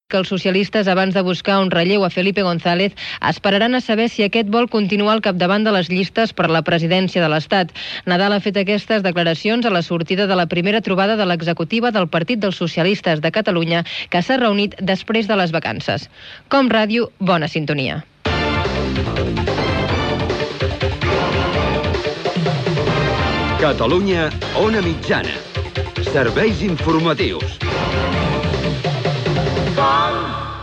Final butlletí de notícies: reunió de l'executiva del Partit Socialista de Catalunya. Indicatiu del programa.
Informatiu